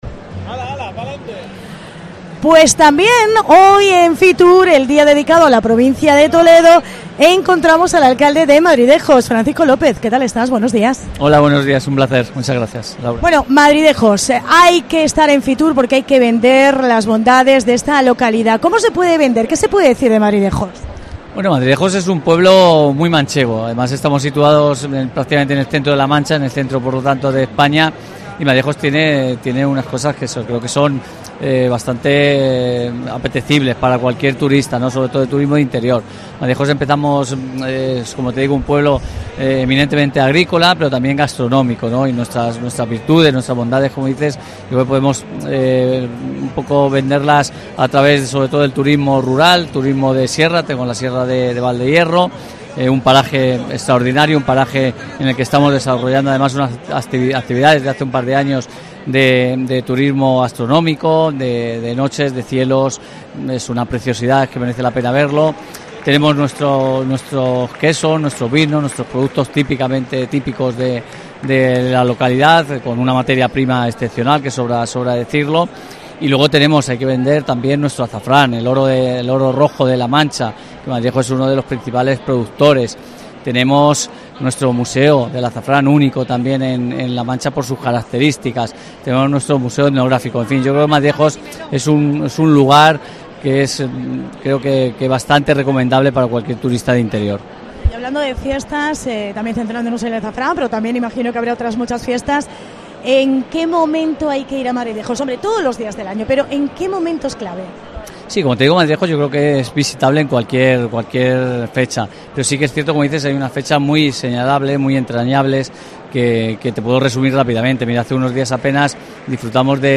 FITUR | Entrevista a Francisco López, alcalde de Madridejos